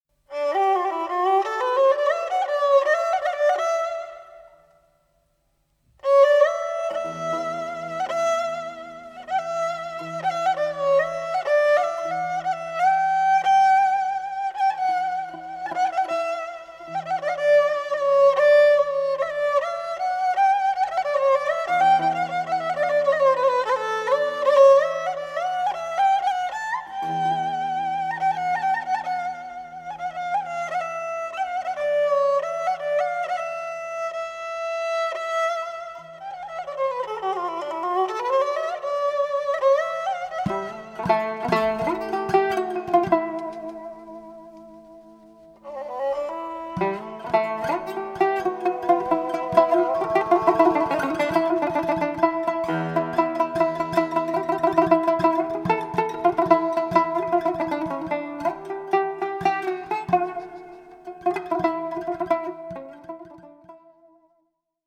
Genre: Turkish & Ottoman Classical.
Tanbur
Classical Kemençe